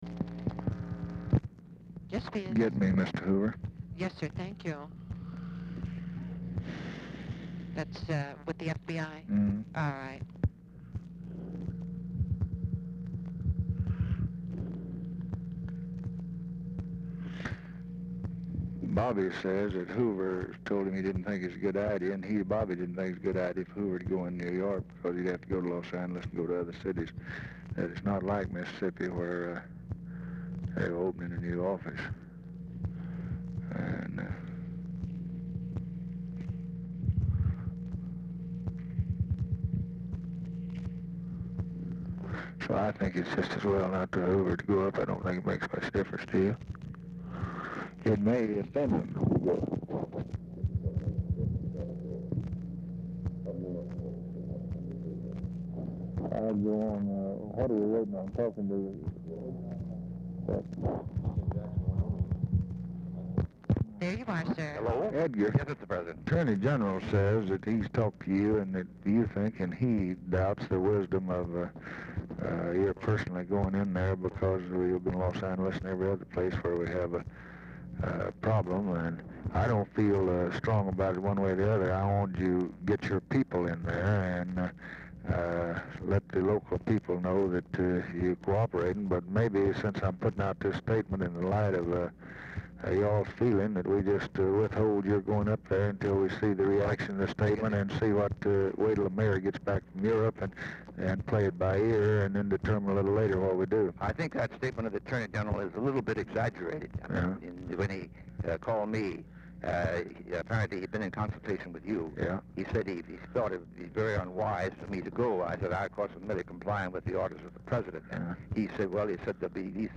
Telephone conversation # 4295, sound recording, LBJ and J. EDGAR HOOVER, 7/21/1964, 1:06PM | Discover LBJ
1:00 OFFICE CONVERSATION PRECEDES CALL